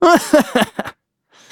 Kibera-Vox_Happy2.wav